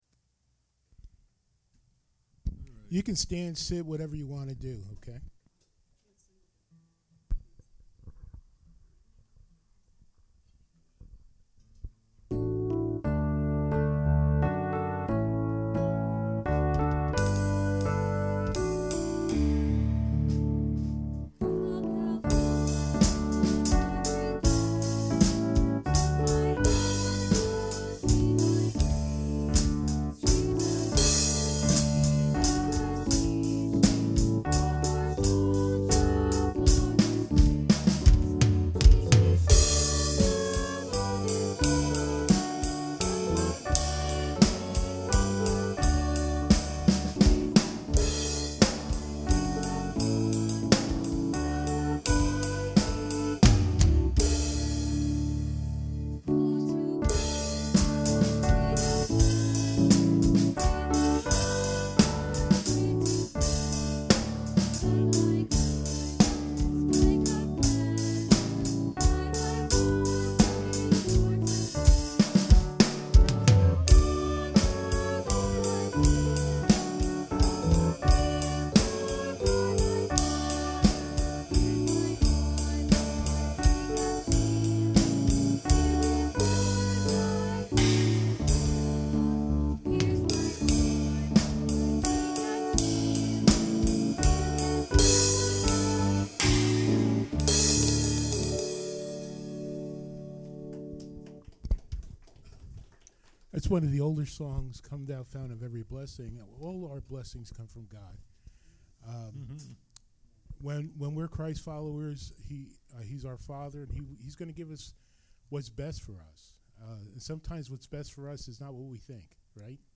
Psalm 111 Preacher